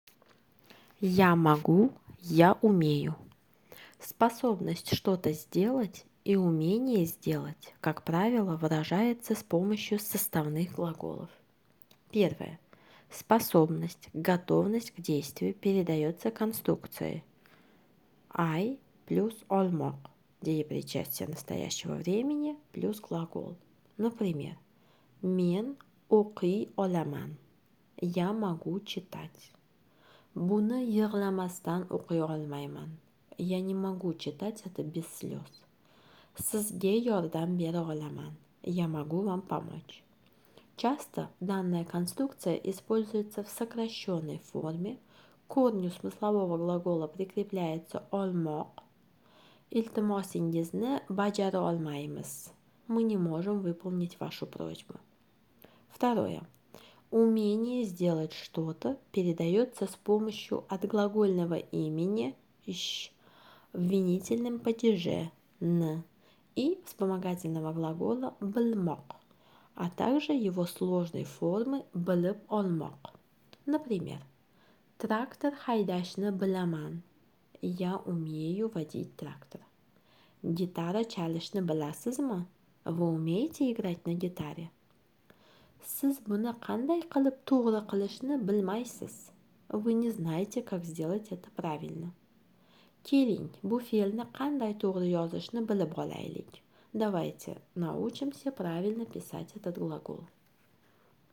Узбекский язык - аудиоуроки